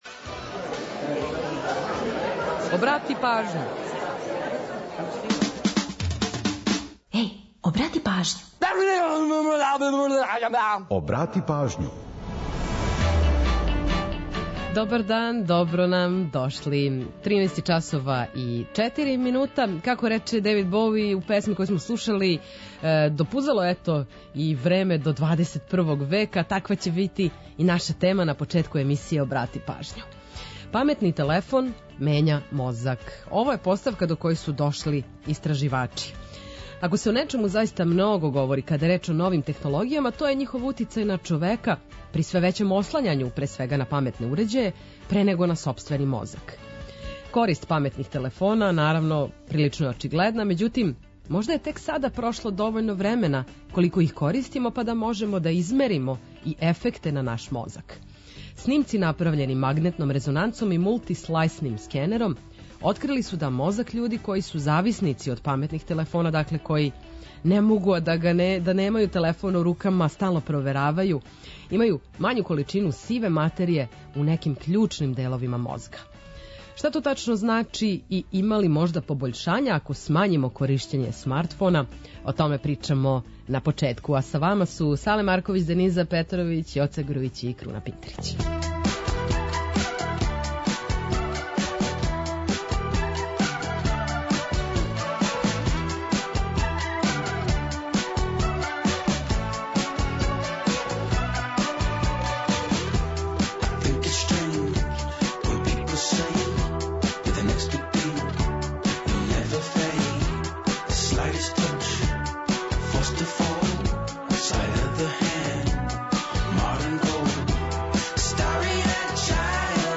Сервисне информације и наш репортер са подацима о саобраћају помоћи ће многима у организовању дана, а „Културни водич” је ту да предложи које манифестације широм Србије можете да посетите.
Ту је и пола сата резервисаних само за нумере из Србије и региона.